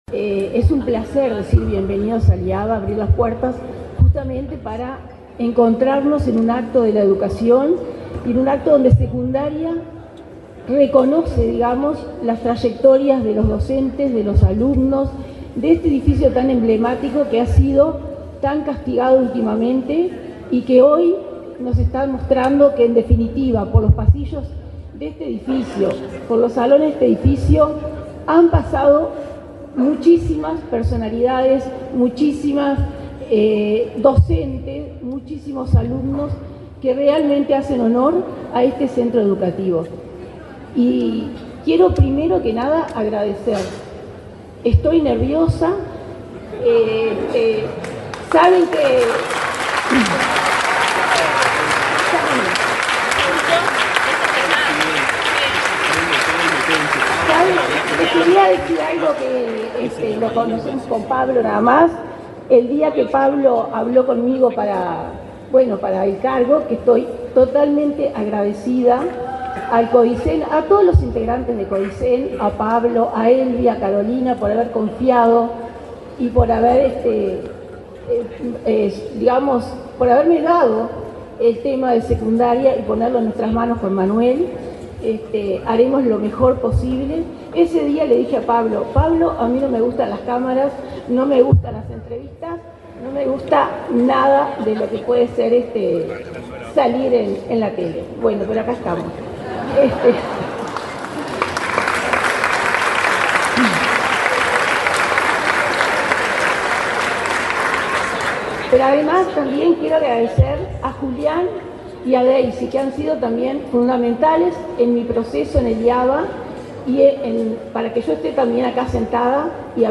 Este miércoles 2, en la sede del liceo IAVA, se realizó la asunción de las nuevas autoridades de la Dirección General de Educación Secundaria (DGES).
Durante la ceremonia, se expresaron, el director y subdirectora de la repartición, Manuel Oroño, y Sandra Peña; el presidente de la Administración Nacional de Educación Pública (ANEP), Pablo Caggiani, y el ministro de Educación y Cultura, José Carlos Mahía.